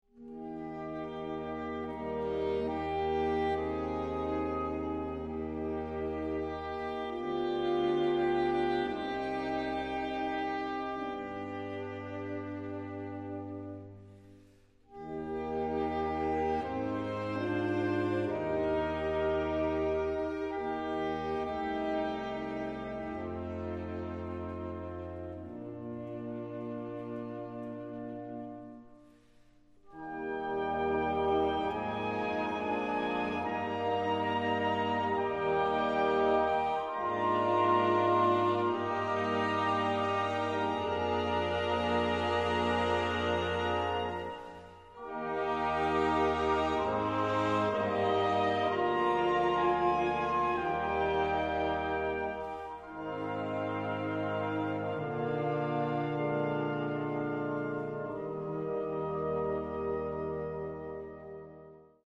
A beautiful demure coral full of lyricism and melodic